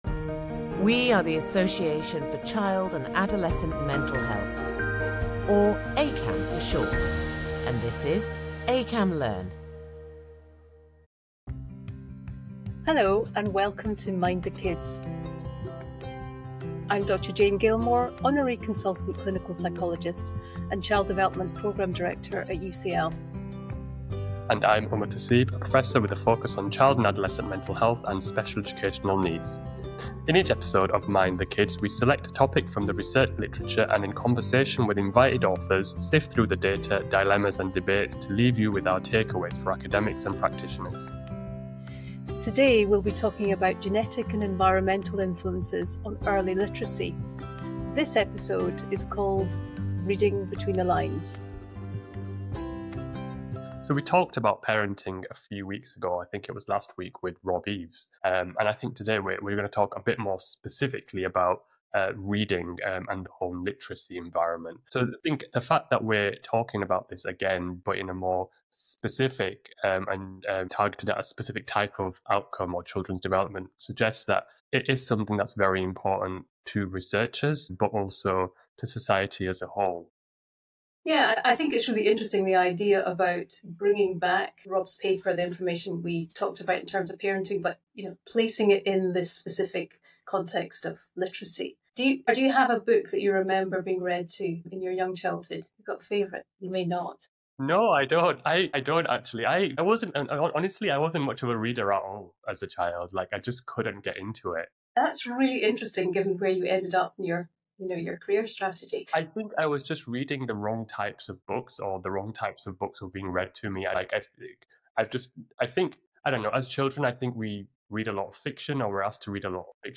the discussion explores how the home literacy environment shapes children’s reading development and long-term outcomes. The conversation highlights the importance of parental involvement, the interplay between genetics and environmental factors, and how early literacy experiences can influence educational success.